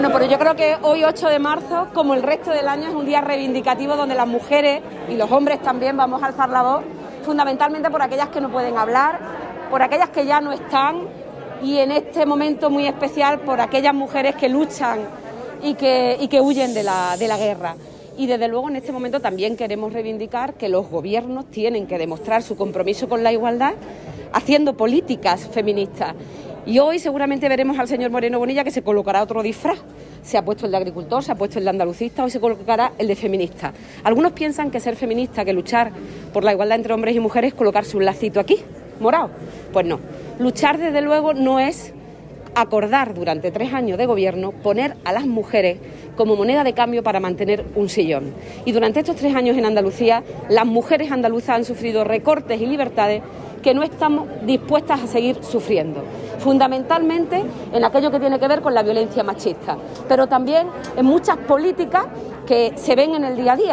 Acto de UGT por el 8 de Marzo
Cortes de sonido